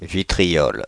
Ääntäminen
Synonyymit huile de vitriol couperose Ääntäminen France (Paris) Tuntematon aksentti: IPA: /vi.tʁi.ɔl/ Haettu sana löytyi näillä lähdekielillä: ranska Käännös Konteksti Substantiivit 1. vetriolo {m} kemia Suku: m .